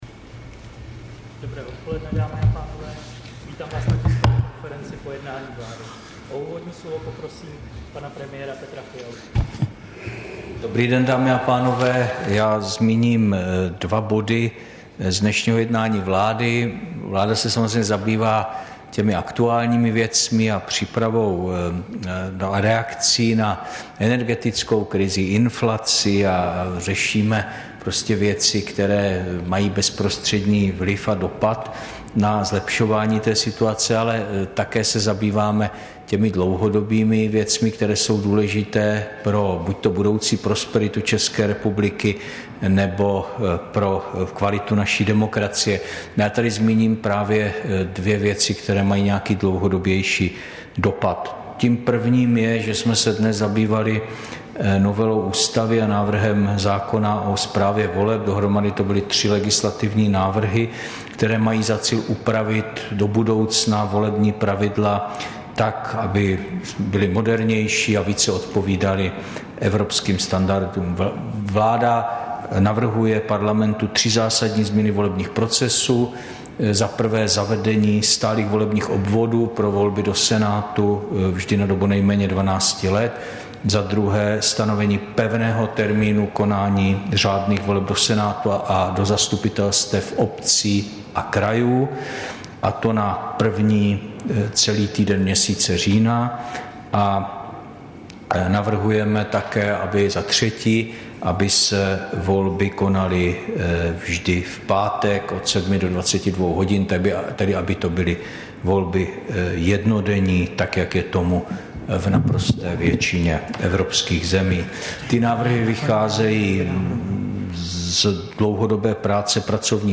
Tisková konference po jednání vlády, 1. února 2023